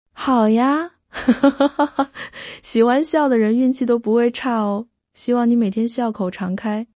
接下来给大家带来的是字节跳动的最新技术，Seed-TTS，一种文本到语音（TTS）模型系列，能生成高质量、几乎和真人语音没两样的语音模型。
Seed-TTS女